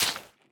Minecraft Version Minecraft Version snapshot Latest Release | Latest Snapshot snapshot / assets / minecraft / sounds / block / sponge / wet_sponge / break3.ogg Compare With Compare With Latest Release | Latest Snapshot